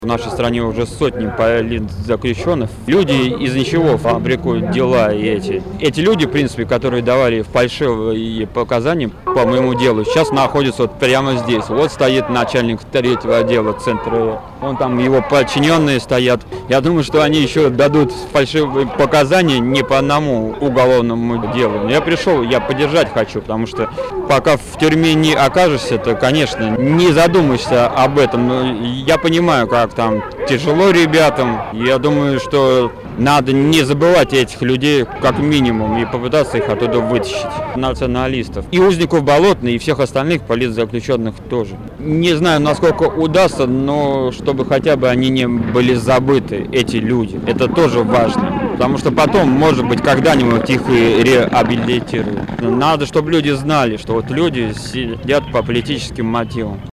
В Петербурге прошел митинг в поддержку политзаключенных. Митинг, организованный "Гражданским комитетом", был согласован, на площади у театра "Балтийский дом" собралось около 100 человек, над которыми развевались флаги "Левого фронта", КПРФ и разных имперских и националистических движений....